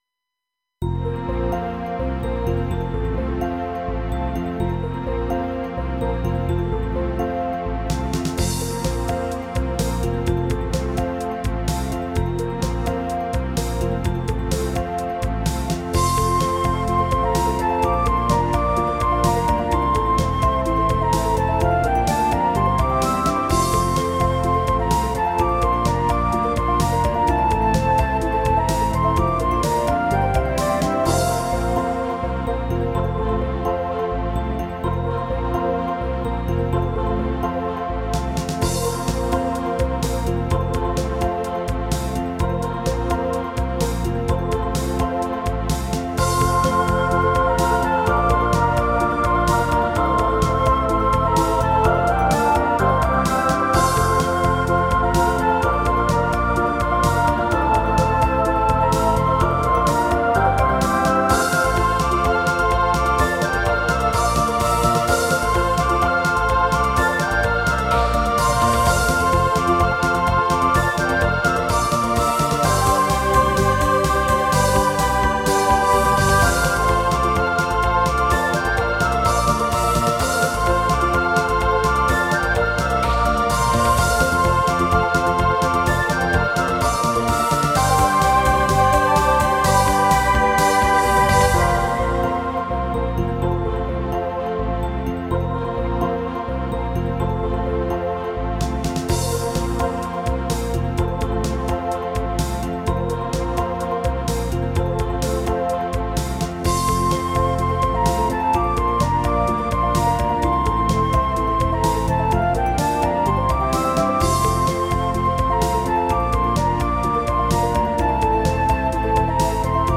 私のオリジナル曲のうち、ゲームミュージック風の曲を公開いたします。